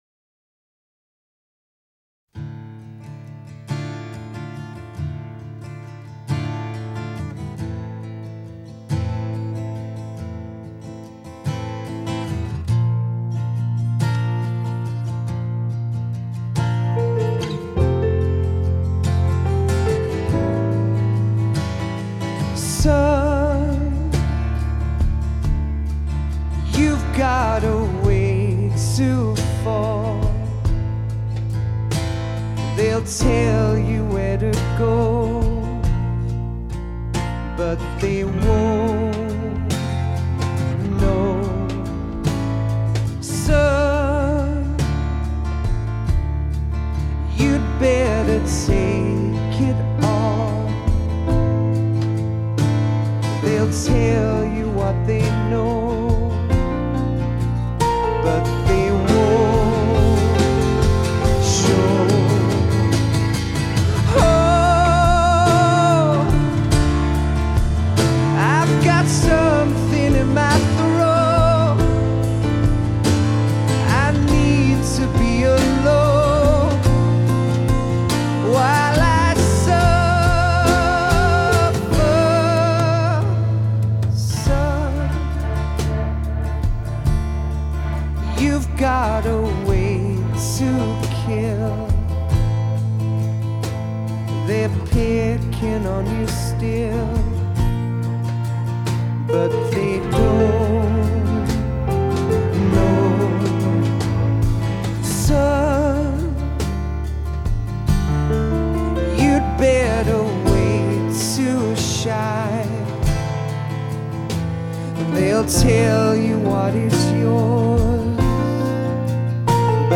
Файл в обменнике2 Myзыкa->Зарубежный рок